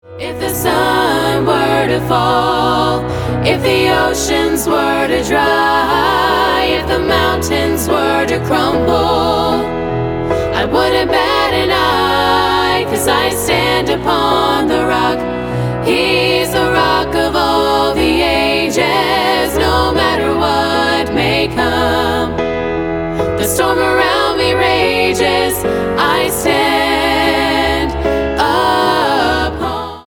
SSA